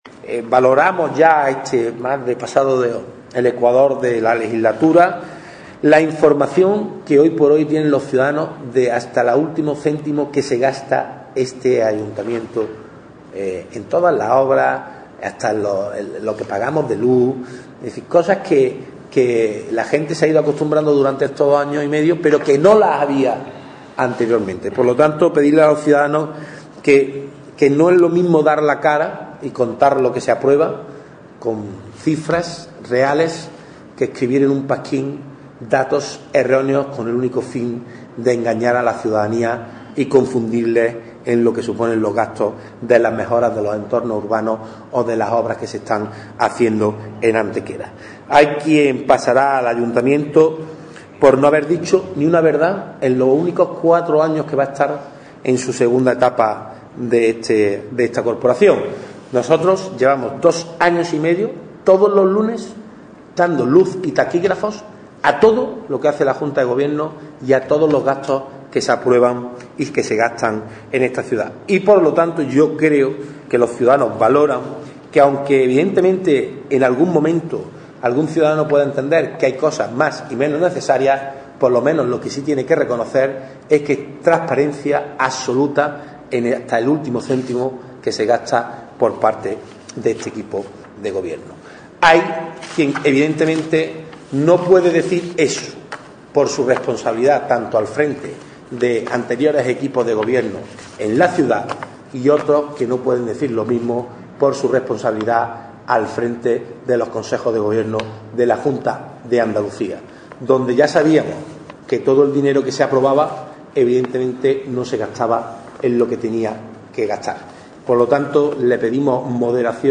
El teniente de alcalde-portavoz del Equipo de Gobierno, Ángel González, ha comparecido en la mañana de hoy ante los medios de comunicación para informar sobre los principales acuerdos adoptados en la sesión ordinaria de hoy de la Junta de Gobierno Local, siguiendo así el compromiso de información y transparencia de la gestión municipal.
Cortes de voz